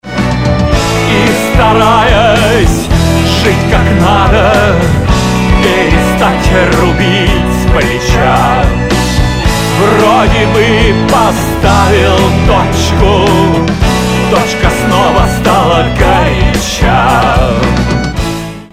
• Качество: 192, Stereo
рок